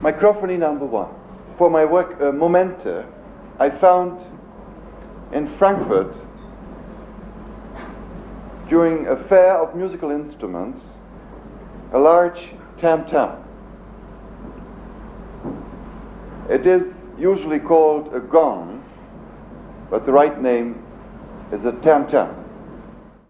Mikrophonie III Stockhausen Speech 1.wav